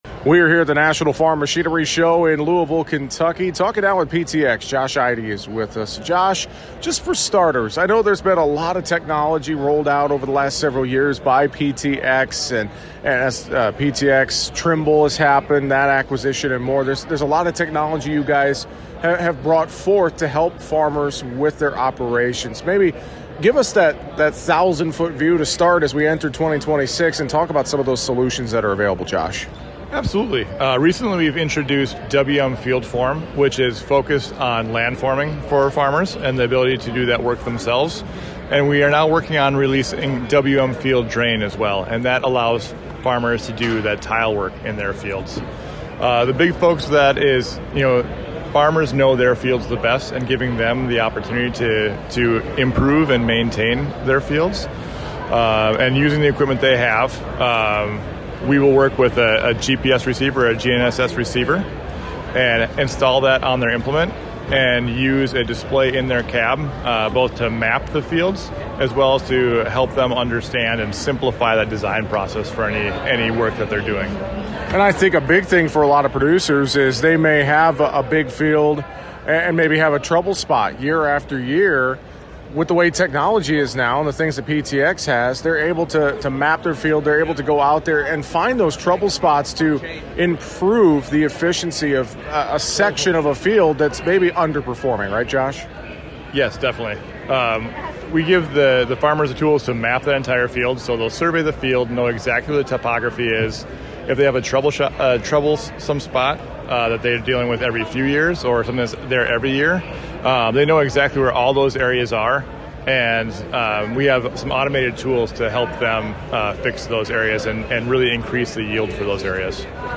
During our time at the National Farm Machinery Show in Louisville, KY this week, we had a conversation about water management, technology and some of the product offerings from PTx and PTx Trimble.